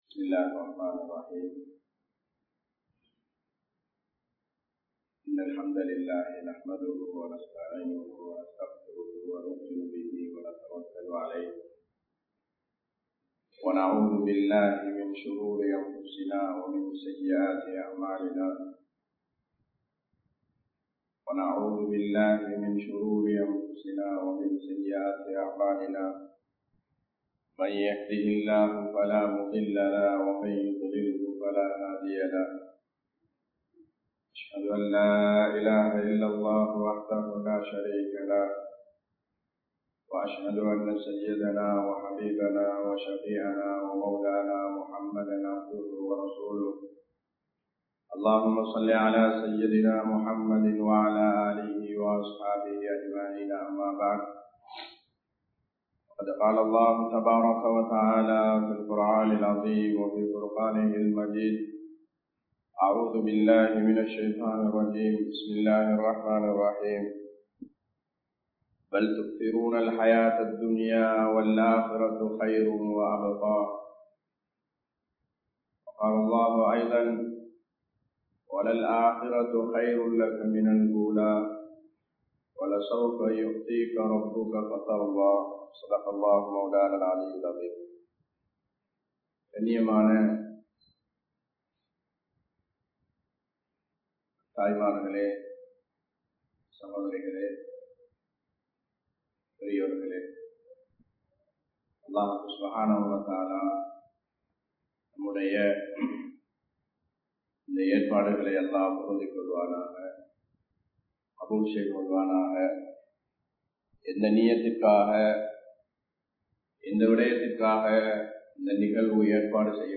Marumaithaan Emathu Ilakku (மறுமைதான் எமது இலக்கு) | Audio Bayans | All Ceylon Muslim Youth Community | Addalaichenai